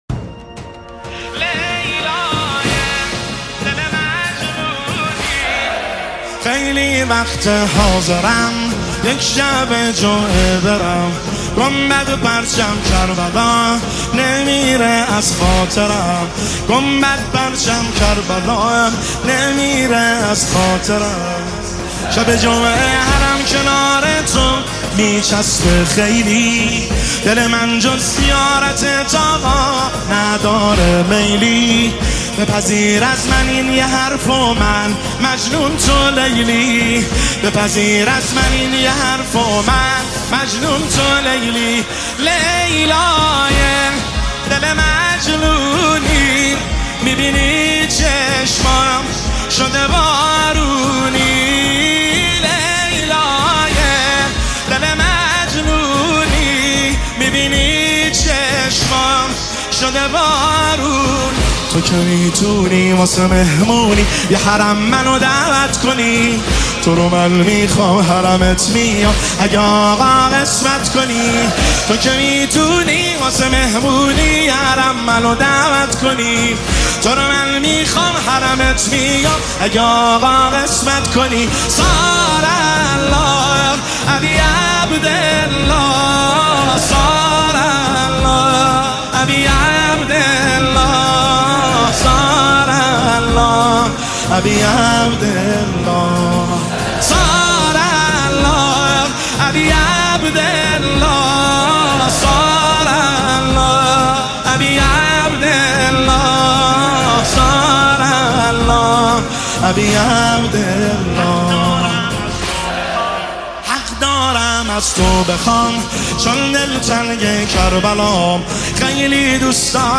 مداحی ماه محرم